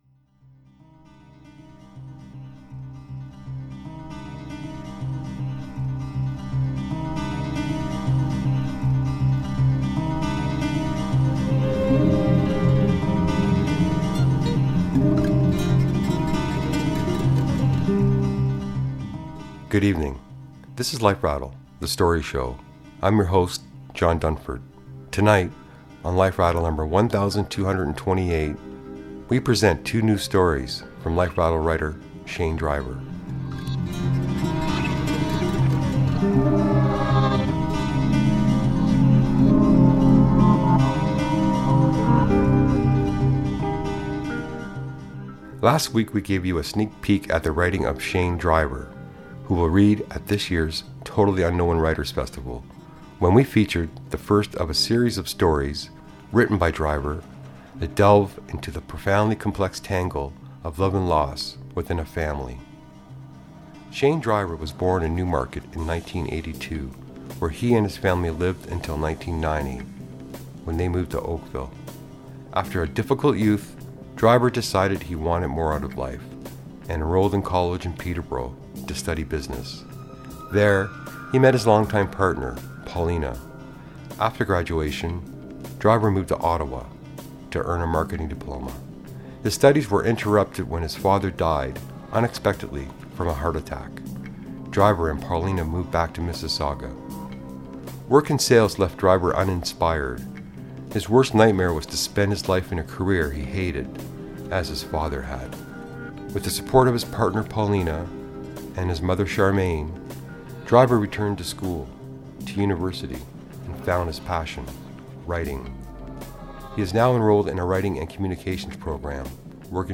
Life Rattle Radio Show No. 1228